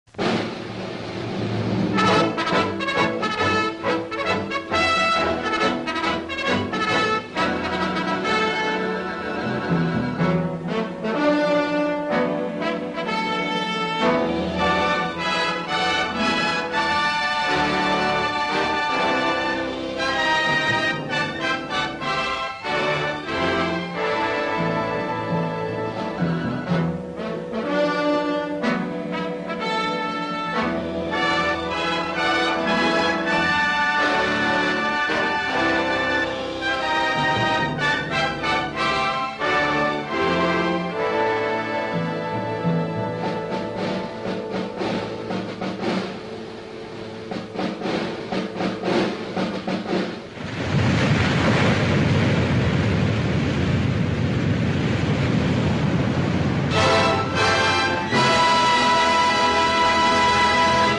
from-here-to-eternity-1953-official-teaser-trailer-burt-lancaster-movie.mp3